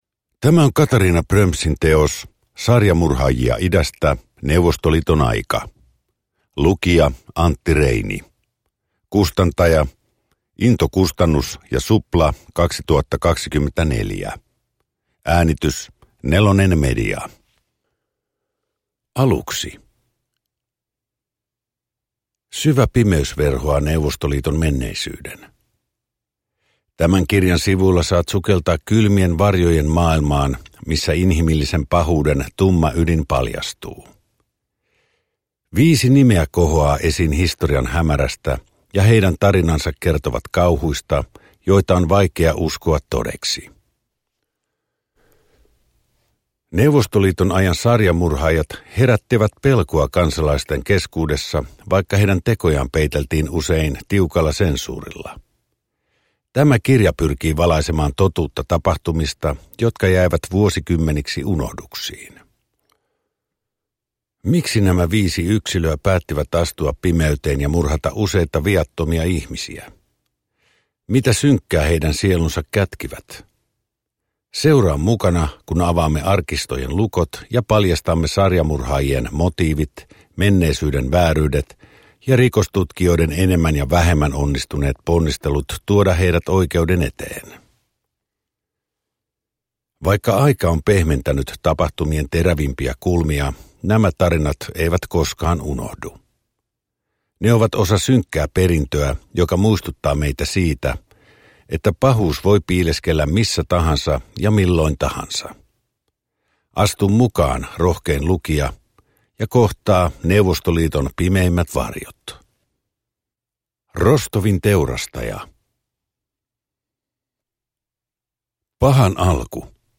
Uppläsare: Antti Reini
Ljudbok